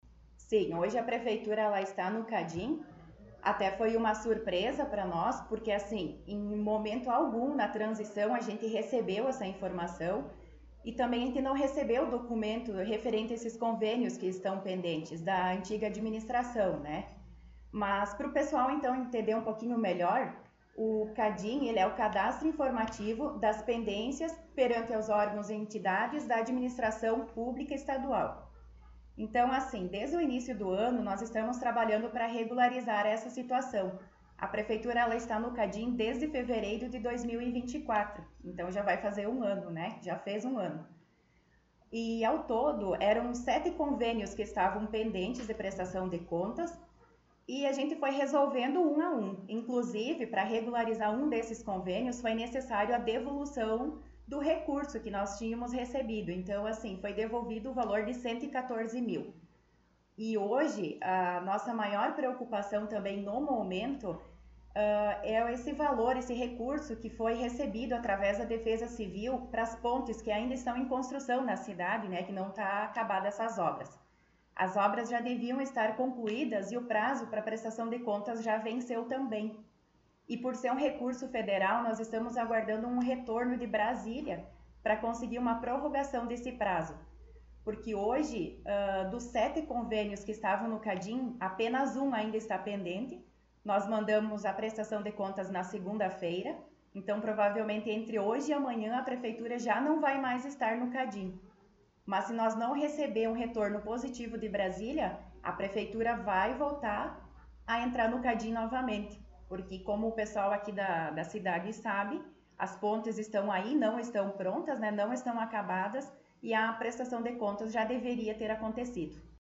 Secretária Municipal de Planejamento concedeu entrevista